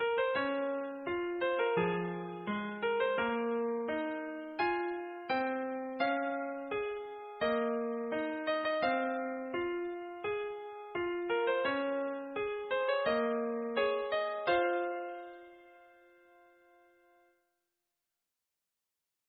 JUST FOCUS ON THE FIELD OF TRADITIONAL MECHANICAL MUSIC BOX